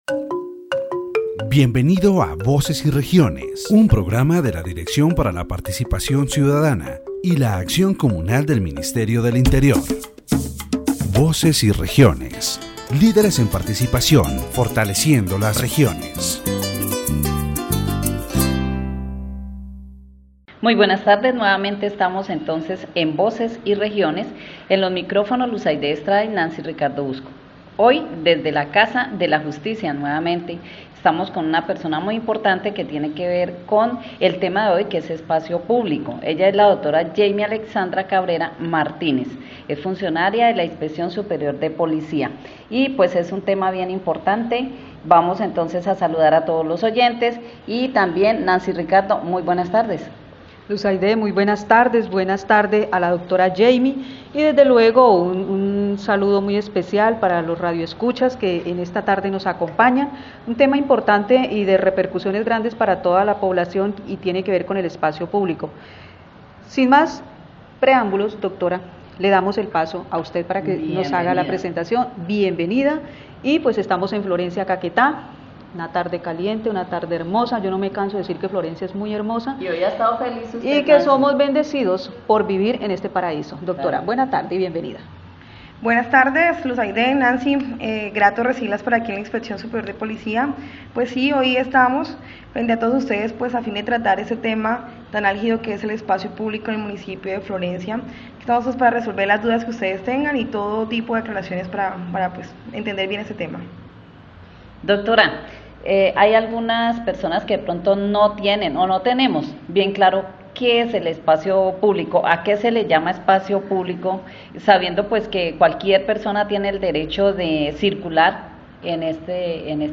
The radio program "Voices and Regions" of the Directorate for Citizen Participation and Communal Action of the Ministry of the Interior addresses the issue of public space in Florencia, Caquetá.